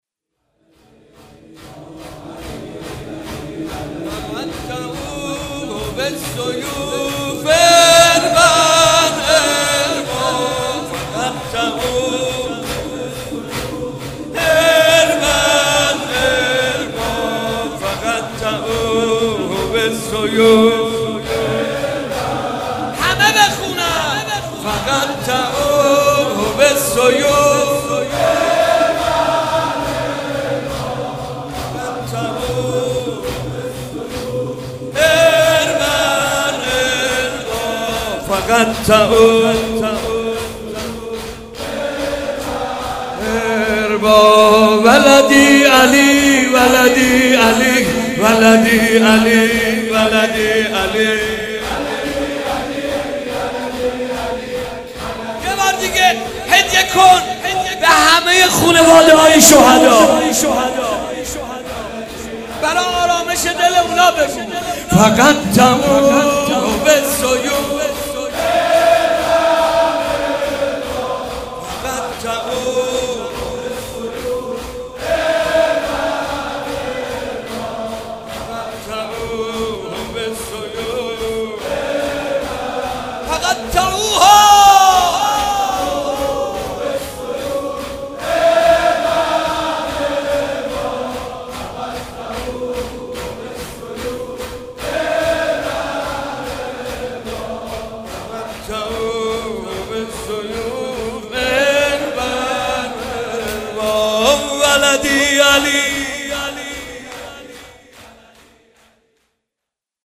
برچسب ها: حاج محمود کریمی مداحی شور روضه دهه اول محرم صراط